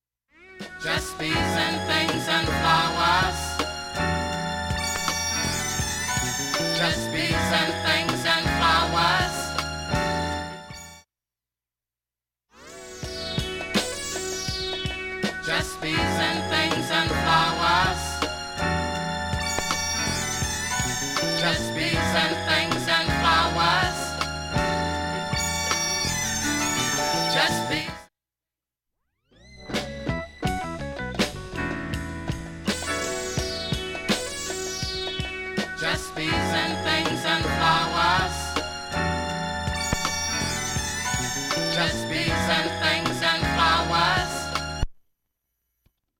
プツ１回出るだけで針は飛びません、
試聴に針圧２グラムのものと
メロウ・クラシック